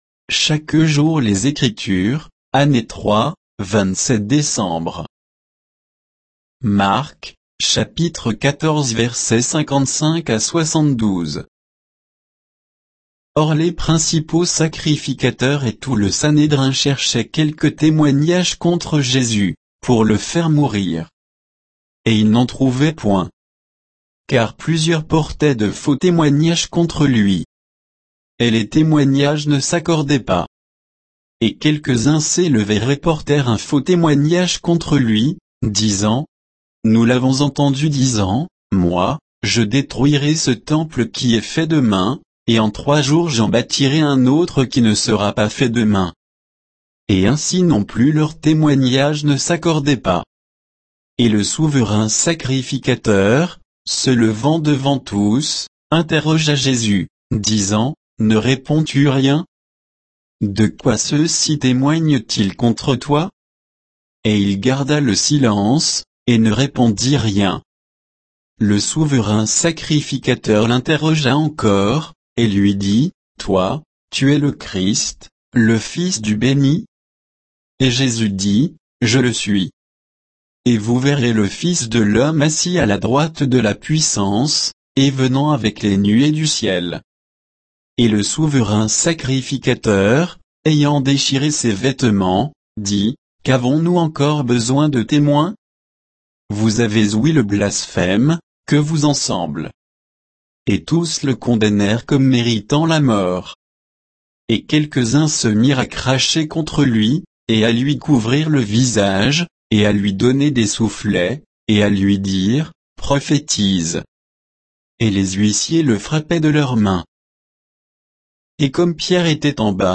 Méditation quoditienne de Chaque jour les Écritures sur Marc 14